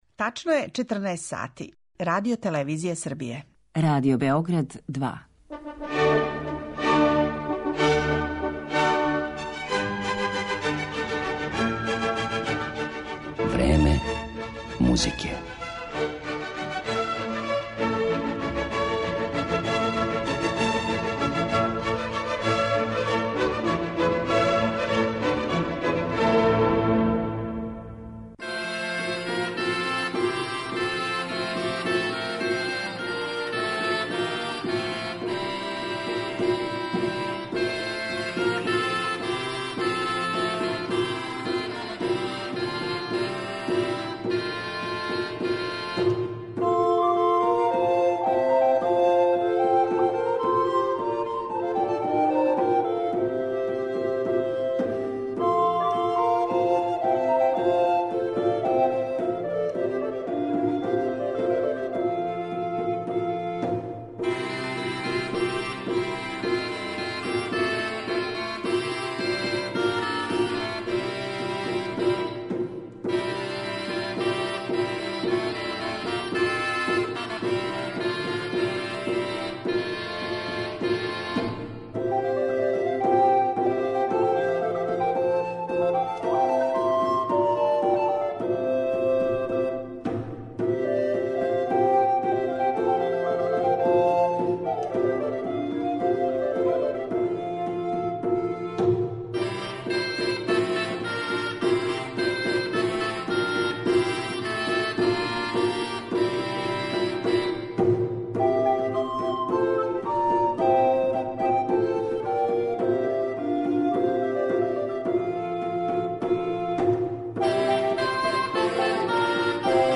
а свира је ансамбл Accademia del ricercare